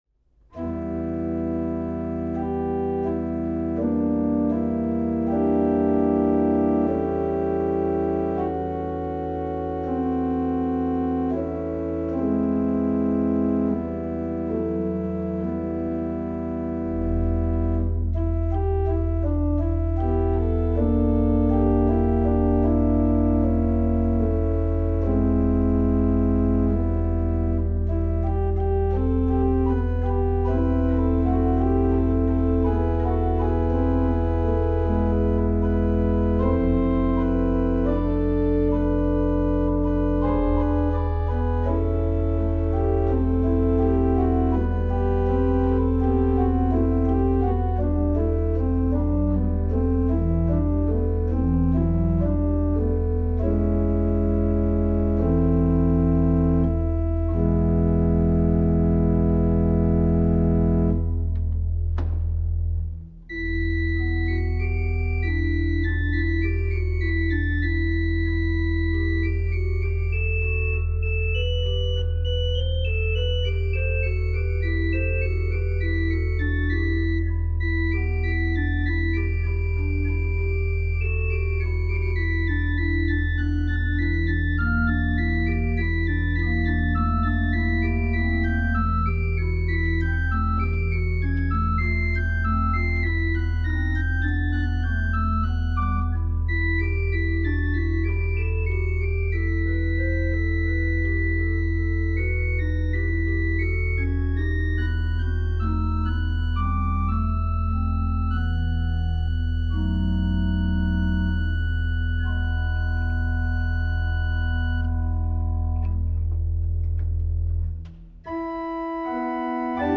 Hier finden Sie Aufnahmen meiner Orgel-Improvisationen über Choräle und Volkslieder.
Erhalt uns Herr bei Deinem Wort - Prinzipalpfeifen 300 Jahre alt